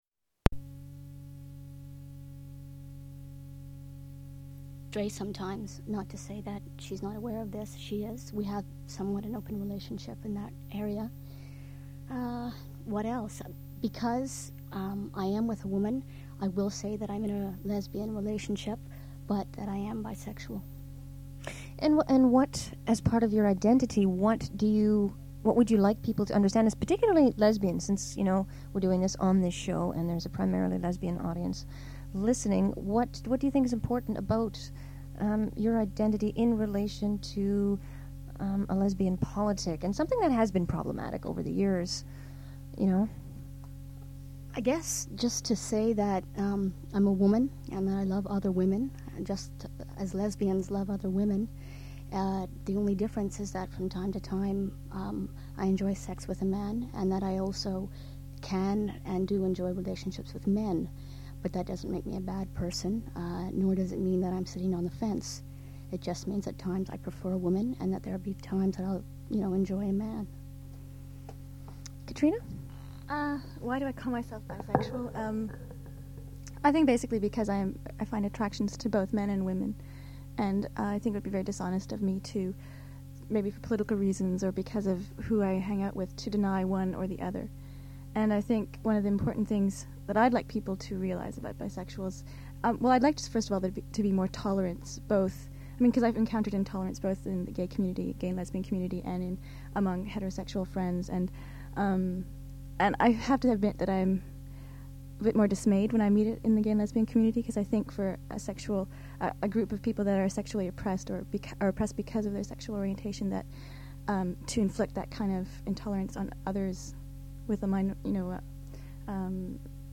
The Dykes on Mykes radio show was established in 1987.